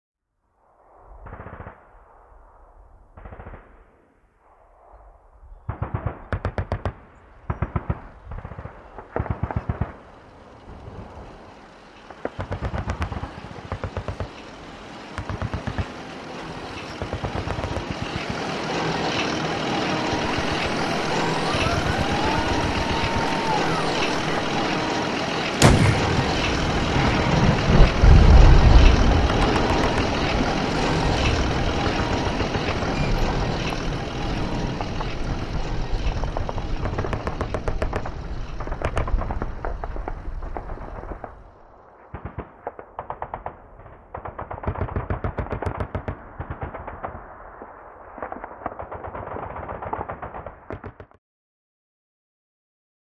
Tank By | Sneak On The Lot
Distant Gunfire Battle With Tank In, By And Fire, Close Point of View.